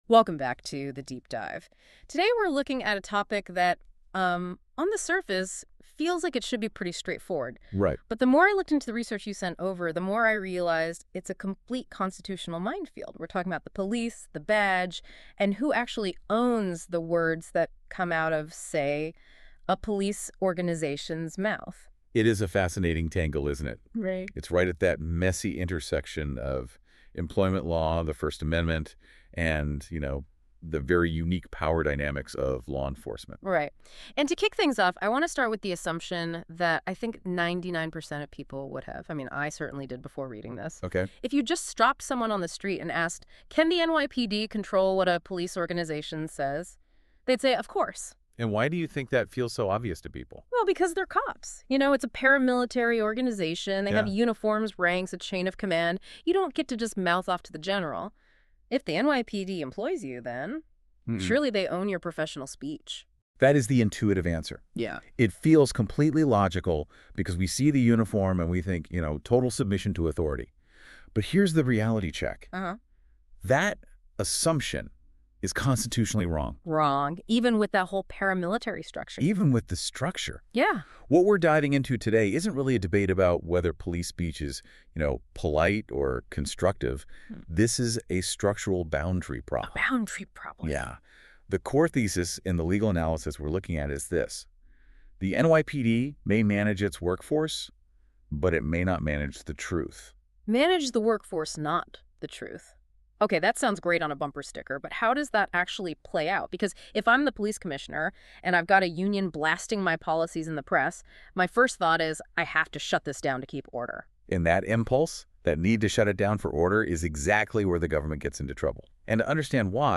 Second, a Deep-Dive Podcast that expands on the analysis in conversational form. The podcast explores the historical context, legal doctrine, and real-world consequences in greater depth, including areas that benefit from narrative explanation rather than footnotes.